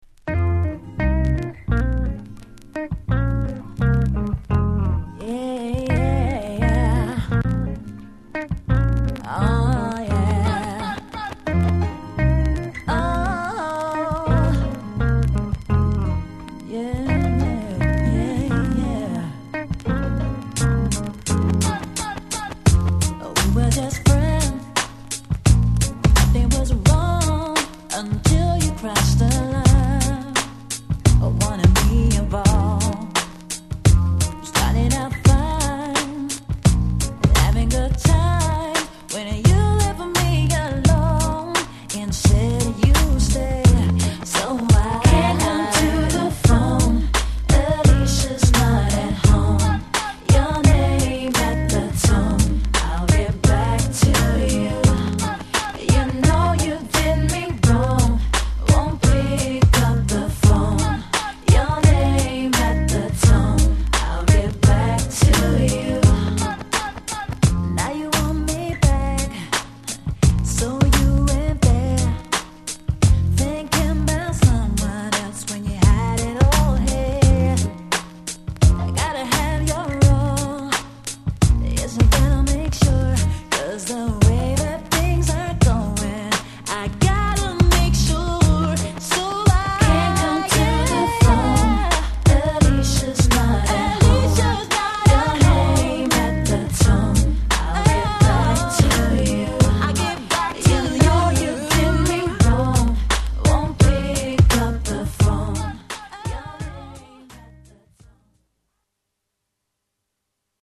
Genre: #R&B